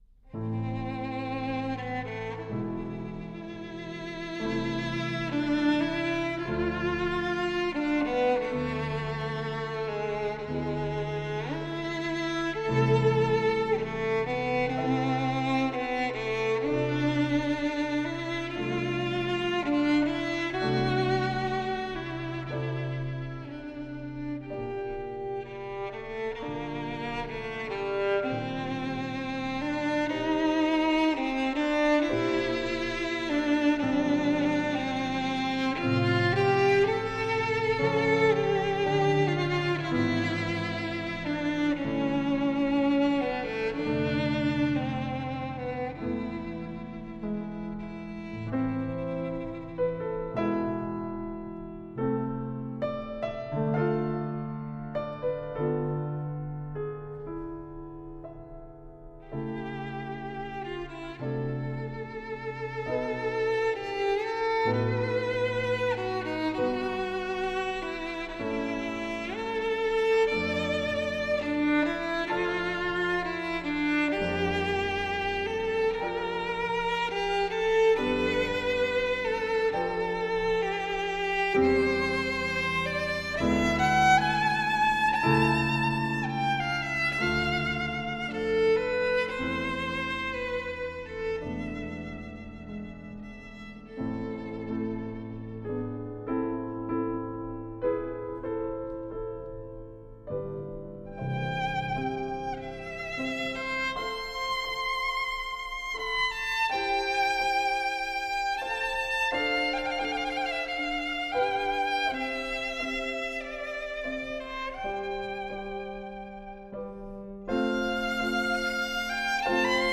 klavír